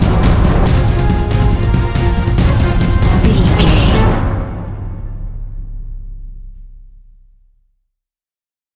Epic / Action
Fast paced
In-crescendo
Aeolian/Minor
A♭
Fast
driving drum beat